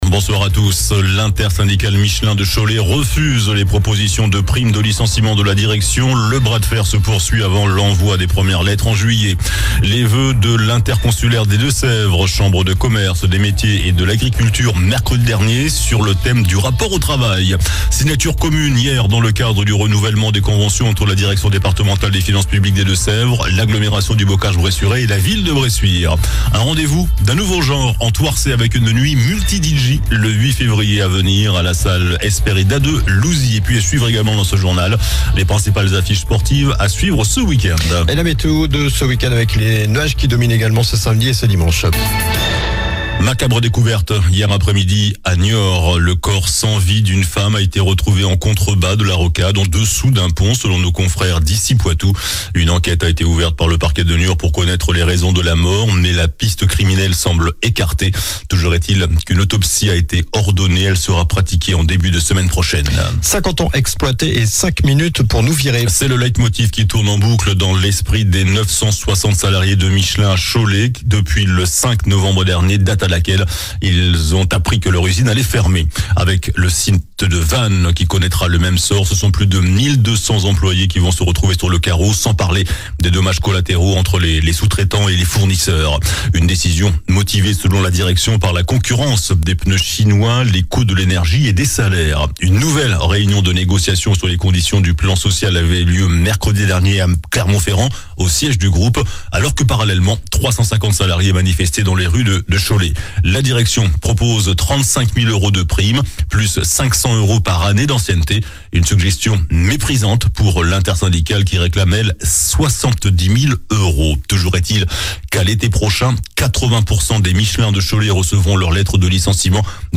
Journal du vendredi 24 janvier (soir)